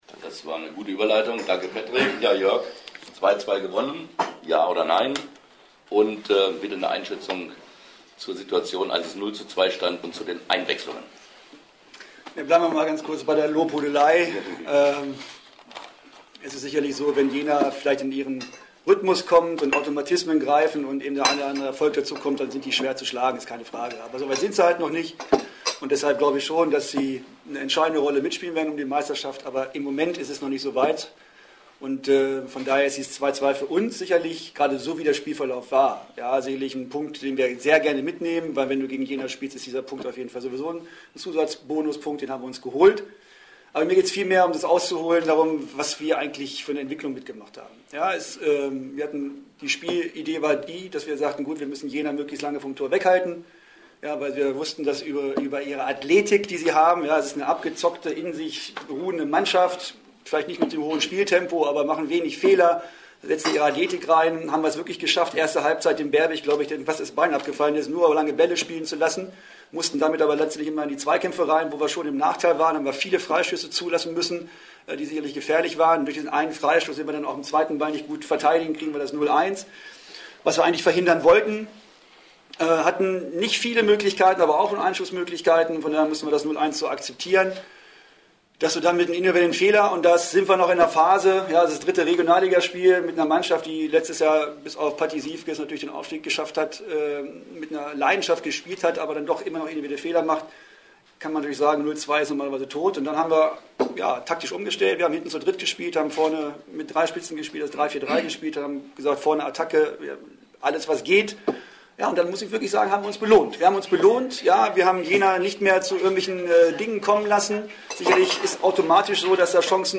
Statements der Trainer
Moderiert wurde die Pressekonferenz von ZDF-Sportreporterlegende Rolf Töpperwien.